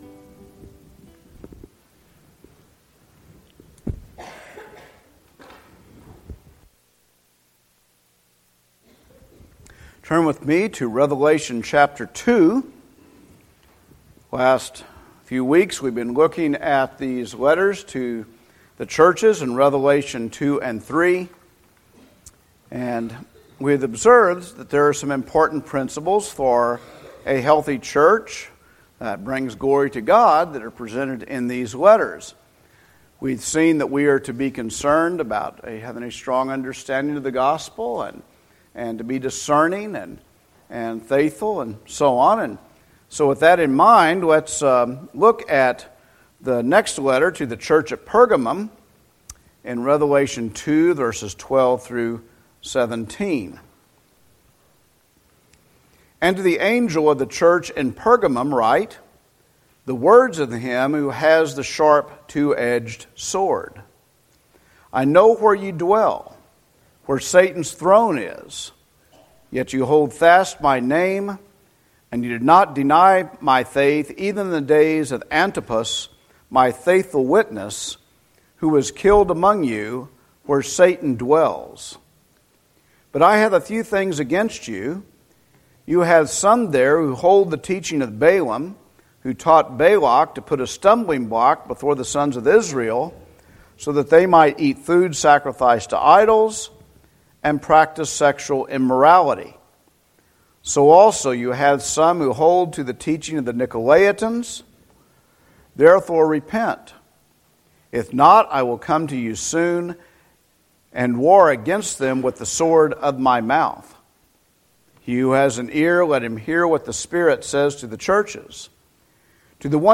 Revelation 2:12-17 Service Type: Sunday Morning Revelation 2:12-17 Persevering in the Faith